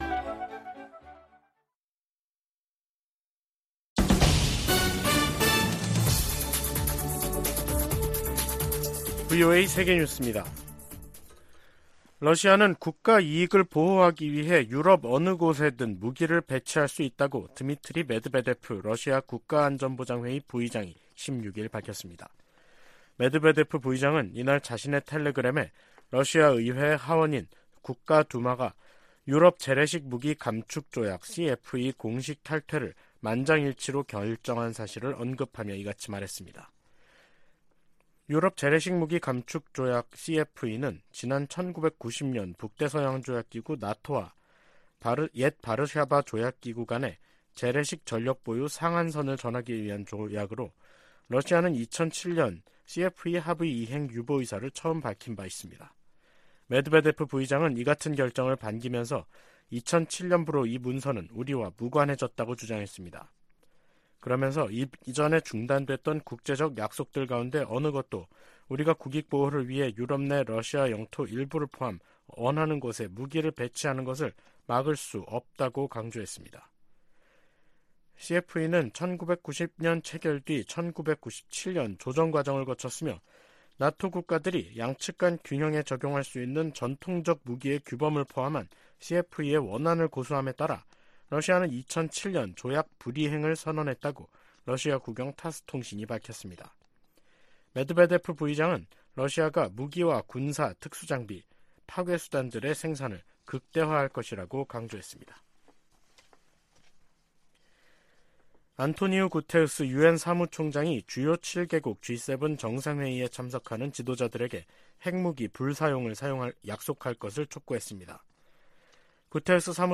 VOA 한국어 간판 뉴스 프로그램 '뉴스 투데이', 2023년 5월 17일 3부 방송입니다. 로이드 오스틴 미 국방장관은 상원 청문회에서 한국에 대한 확장억제 강화조치를 취하는 중이라고 밝혔습니다. 북한은 우주발사체에 위성 탑재 준비를 마무리했고 김정은 위원장이 '차후 행동계획'을 승인했다고 관영매체들이 전했습니다. 미 국무부가 화학무기금지협약(CWC) 평가회의를 맞아 북한이 생화학무기 프로그램을 보유하고 있다는 평가를 재확인했습니다.